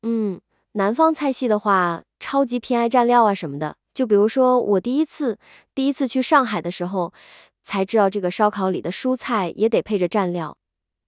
具体来说，火山语音团队最新发布的超自然对话语音合成技术相较传统TTS更加真实自然，即语气词、吸气声、犹豫时的停顿以及字音拖长等细节统统被完美复现，而且只需常规音库1/4数据，就可完美还原真人说话细微的韵律特点、发音口癖，让合成效果更加真实。